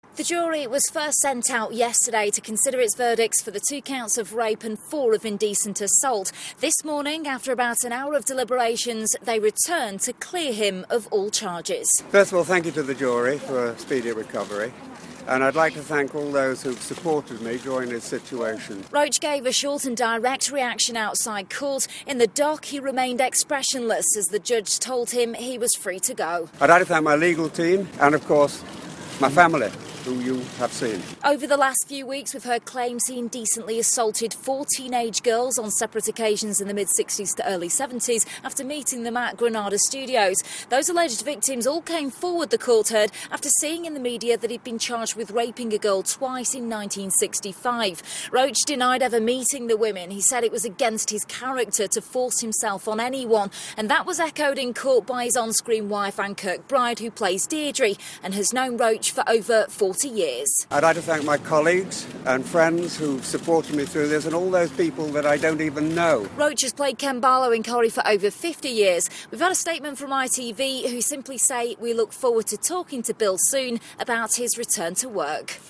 outside Preston Crown Court: